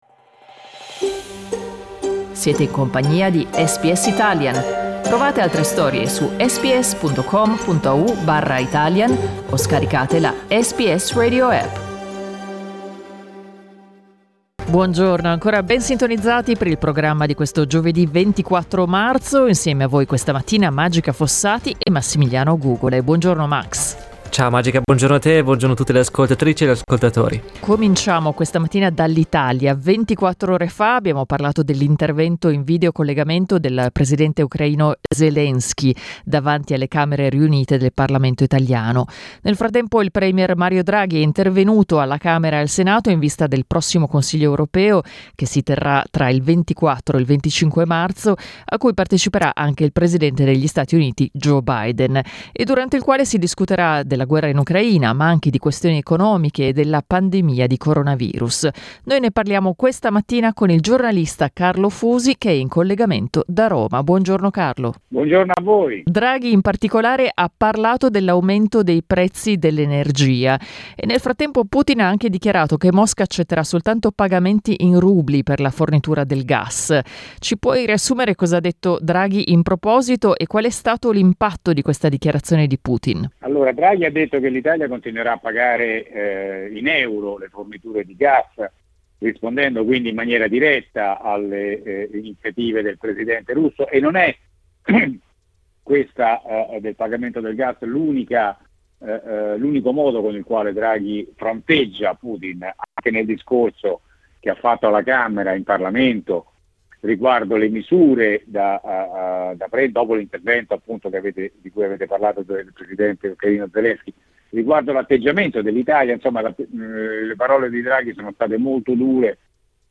in collegamento da Roma